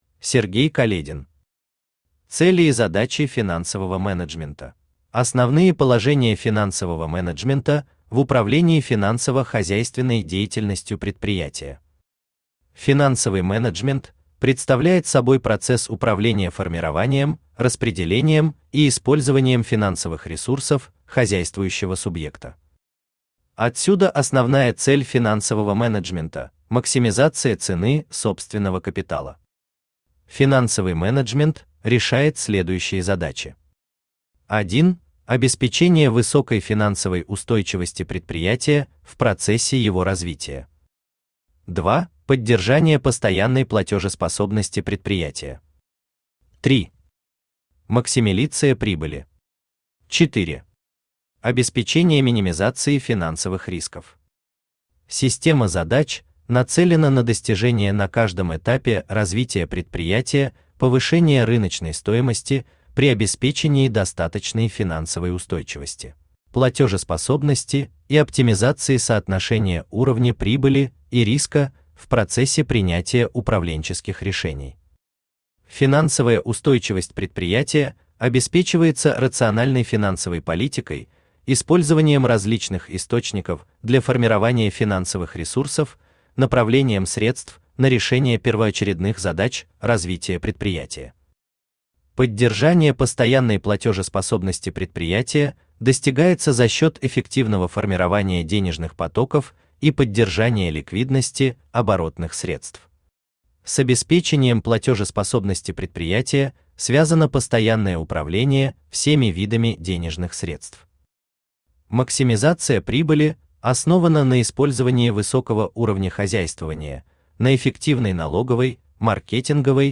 Аудиокнига Цели и задачи финансового менеджмента | Библиотека аудиокниг
Aудиокнига Цели и задачи финансового менеджмента Автор Сергей Каледин Читает аудиокнигу Авточтец ЛитРес.